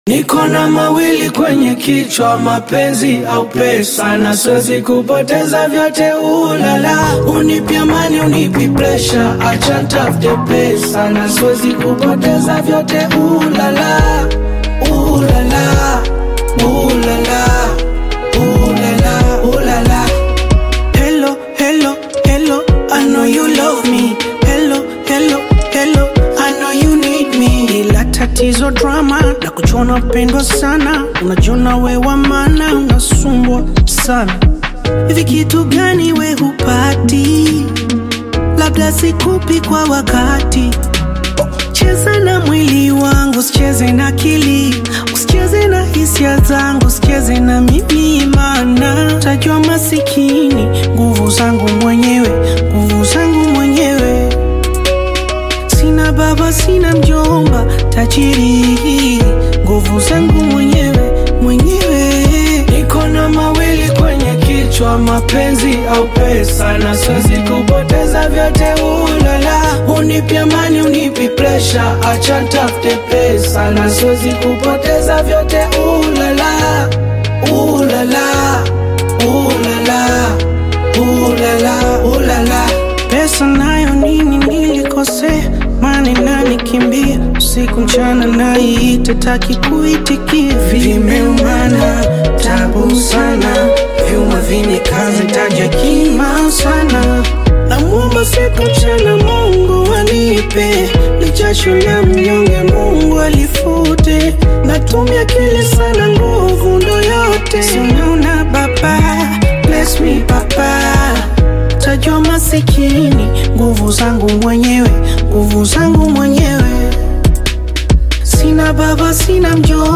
Tanzanian bongo flava artist
African Music